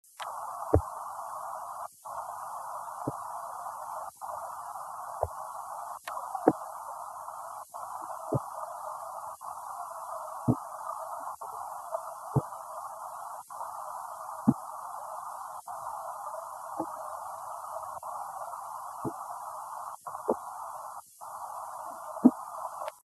Sound produced yes, active sound production
Type of sound produced thumps, knocks
Sound production organ swim bladder
Behavioural context under duress (manual & electric stimulation)
Remark recorded with bandpass filter: 30 - 1200 Hz, high pass noise reduction filter applied to recording